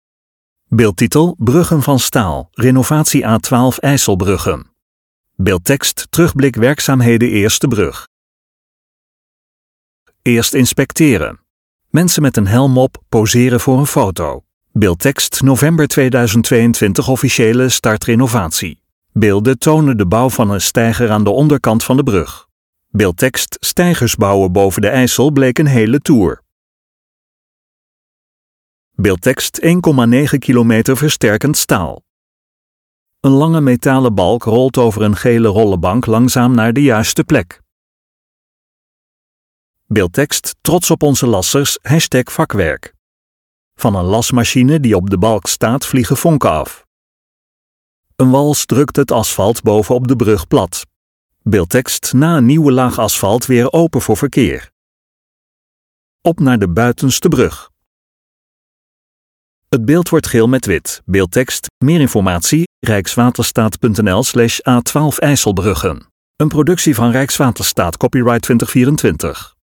LEVENDIGE MUZIEK TOT HET EIND VAN DE VIDEO (Beelden tonen de bouw van een steiger aan de onderkant van de brug.